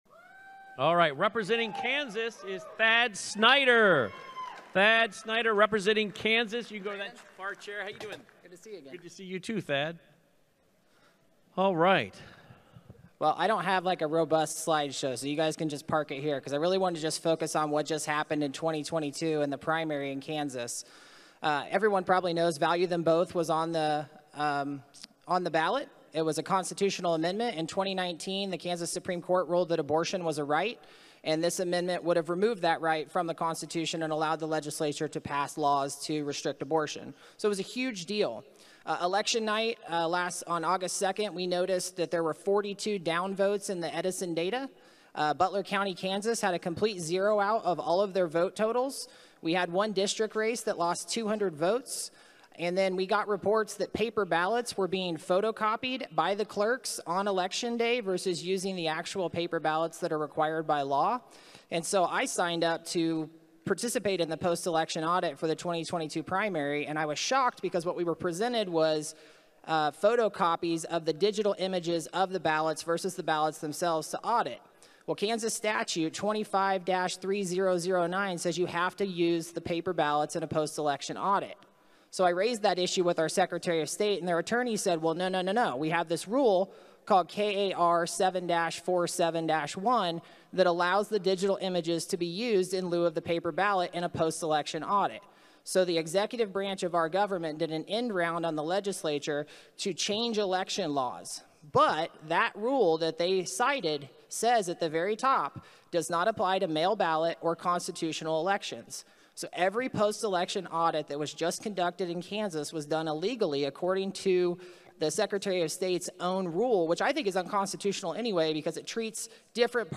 2022 KS Moment of Truth Summit State of the States Presentation audio – Cause of America
presentation